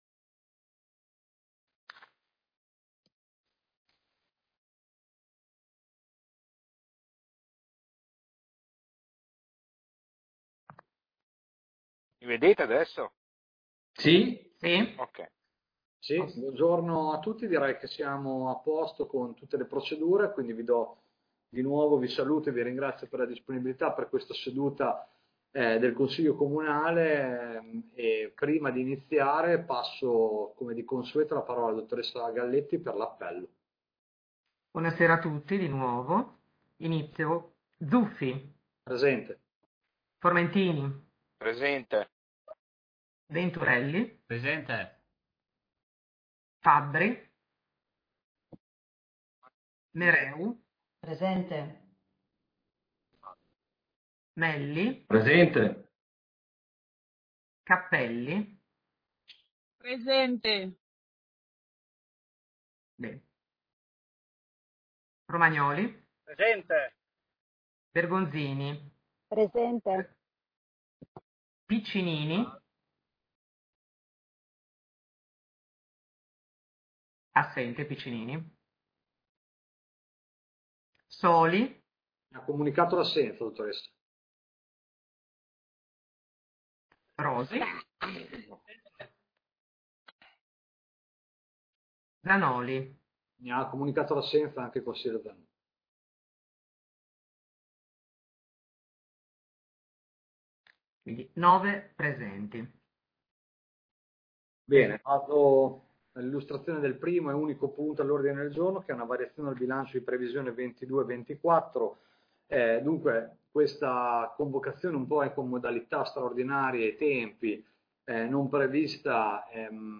Consiglio Comunale del 4 agosto 2022